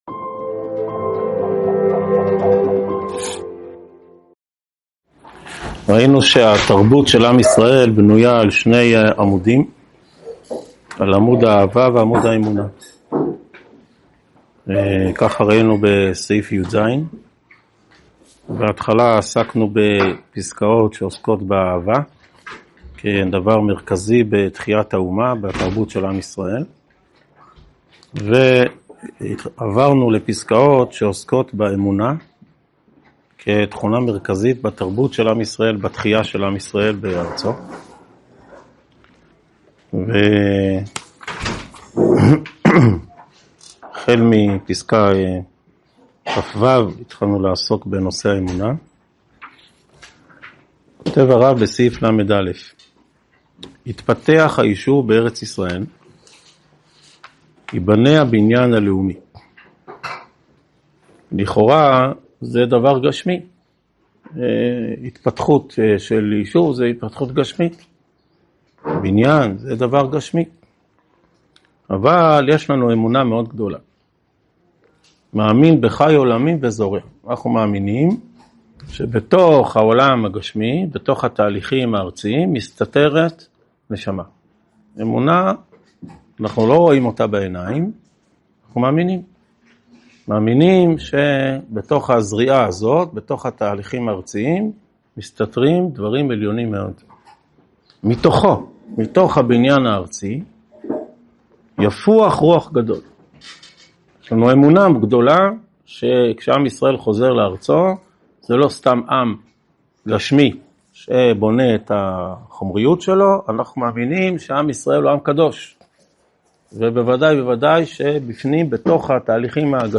שיעור 36 מתוך 59 בסדרת אורות התחיה
הועבר בישיבת אלון מורה בשנת תשפ"ה.